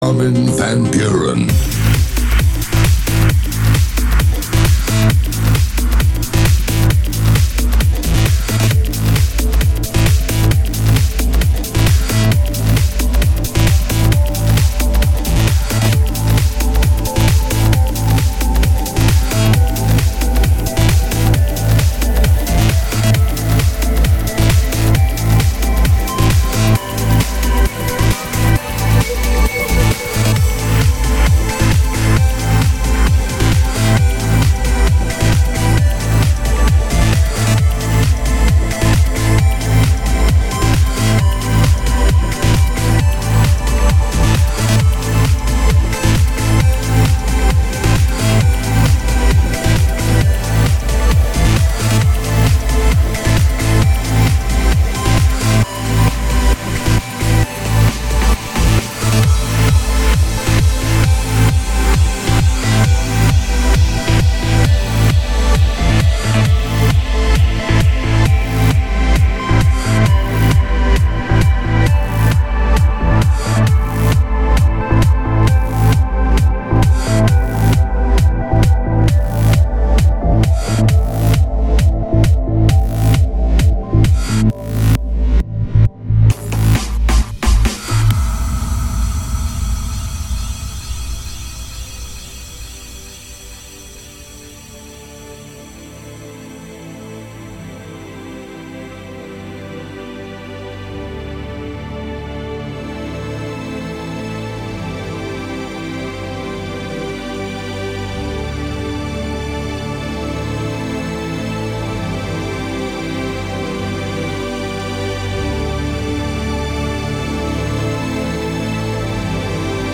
Категория: Club & Dance